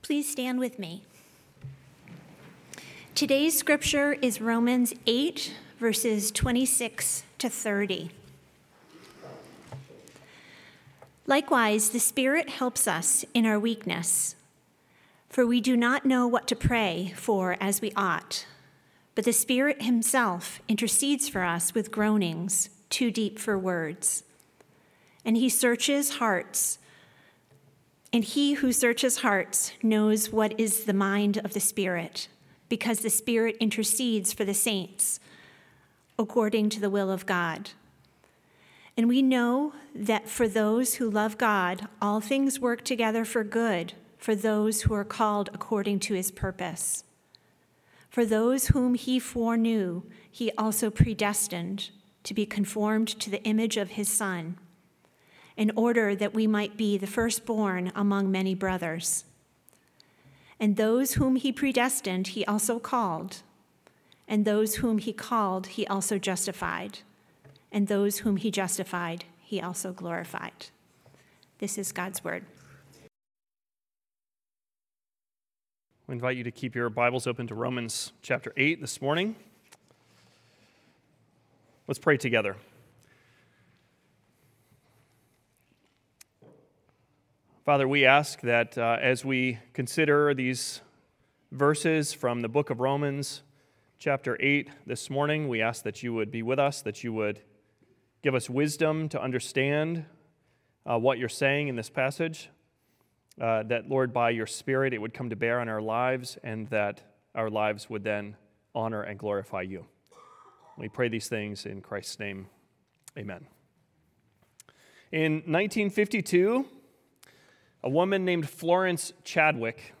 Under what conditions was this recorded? The preaching ministry of Westgate Church (Weston, MA)